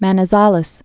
(mănĭ-zälĭs, -zălĭs, mänē-sälĕs)